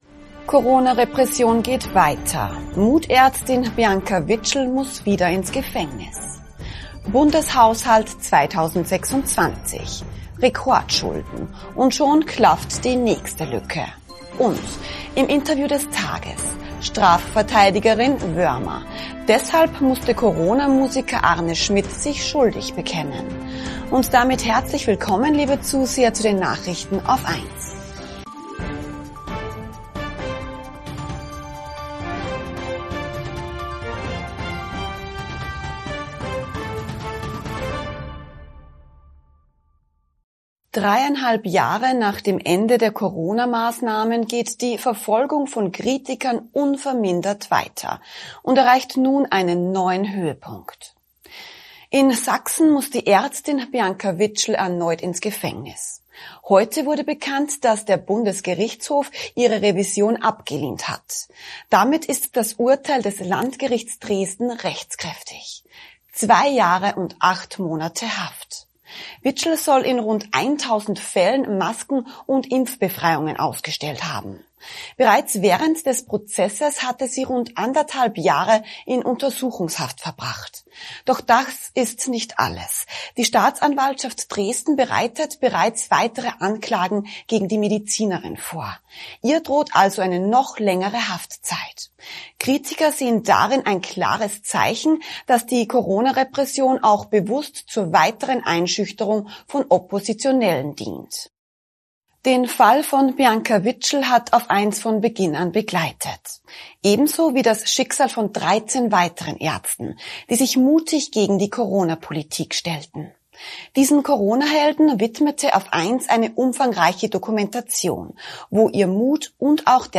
Im Interview des Tages